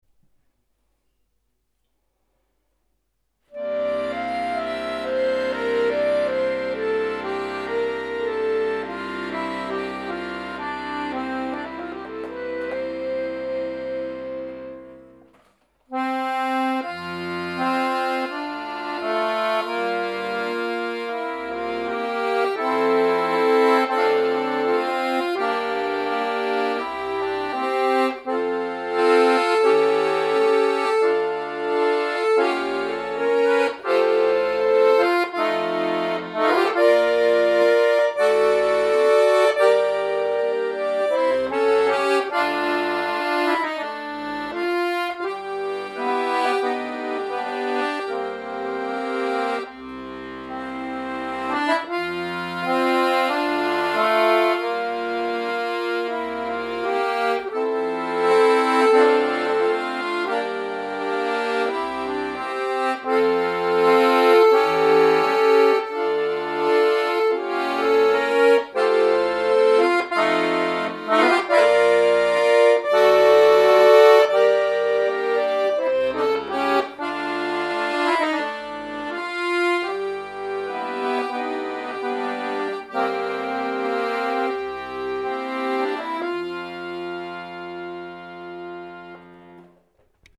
ok! Hier kommt mein Rätsel, eben schnell aufgenommen.